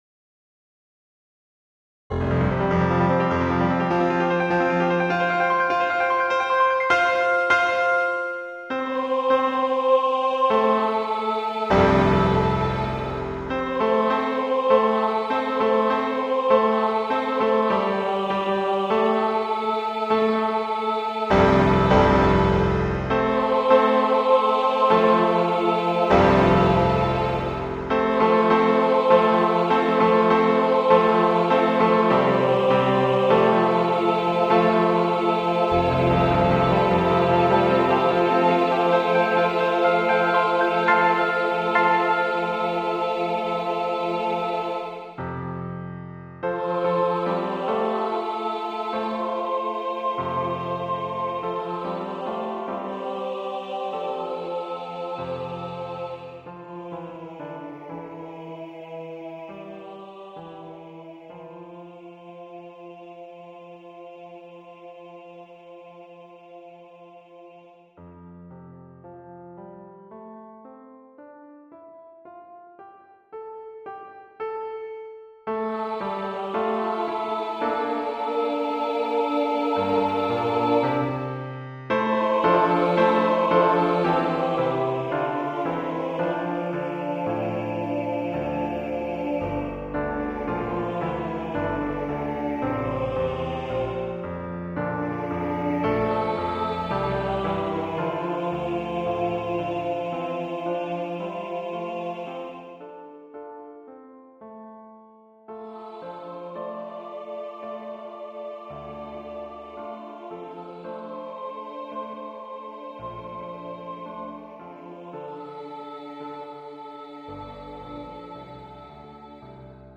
ANTHEM FOR PALM SUNDAY
SAB and piano